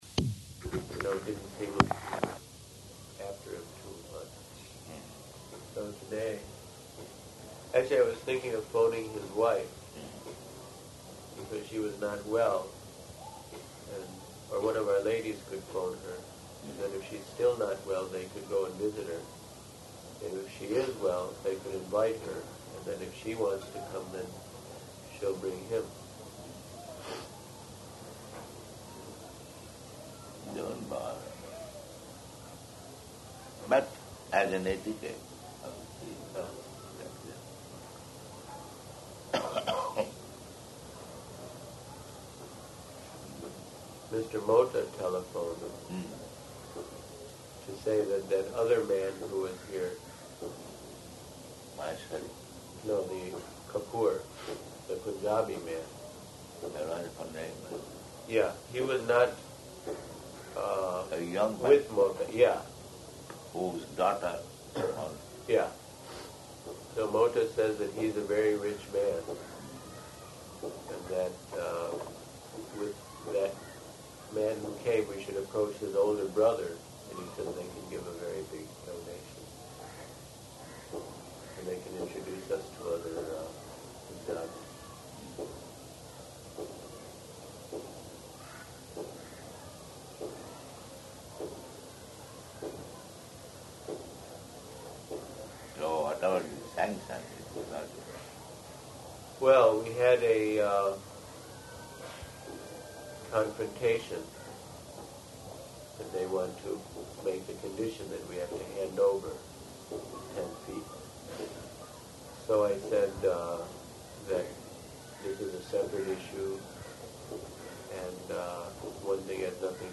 Room Conversation
-- Type: Conversation Dated: January 6th 1977 Location: Bombay Audio file